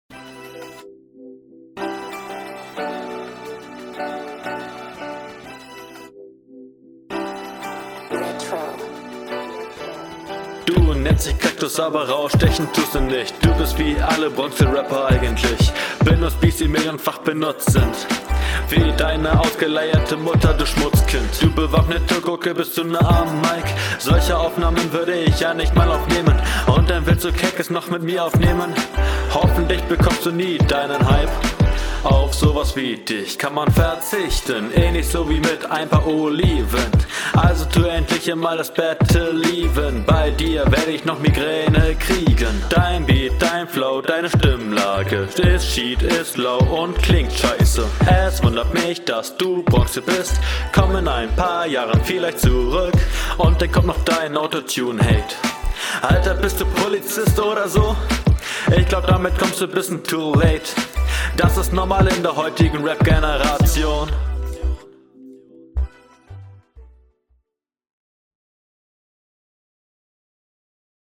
Flow: nur weil du variierst heißt es nicht, dass es gut ist, furchtbar deplatzierte Pausen.